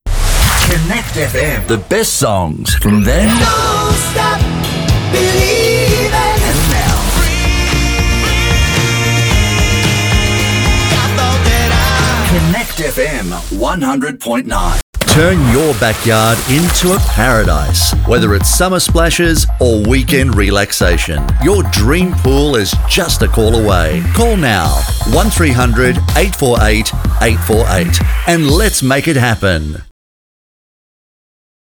Deep Warm Voice Very Masculine 24 Hours or Less Movie trailer Corporate Phone systems Narrations Commercial Spots For more demos
Spanish (Latin American)